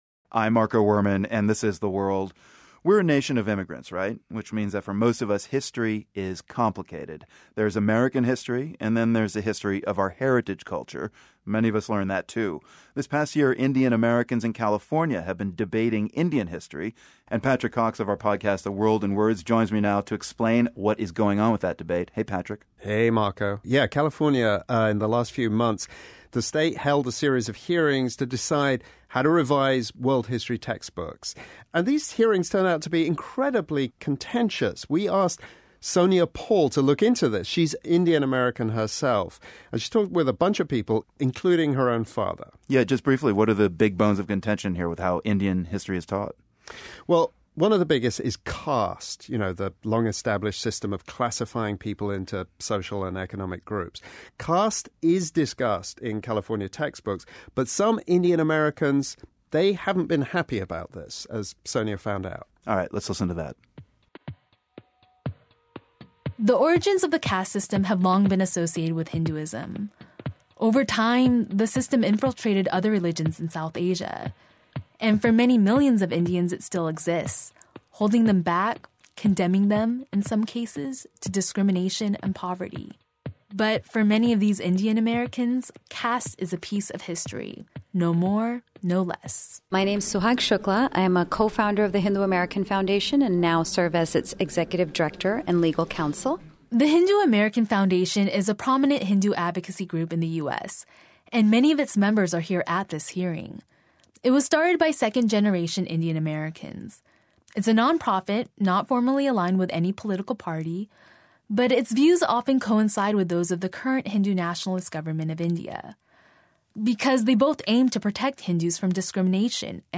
An excerpt from my story on the controversy over how to depict ancient Indian — or what some would prefer to call South Asian — history in California middle school textbooks aired on PRI’s The World on May 2, 2017.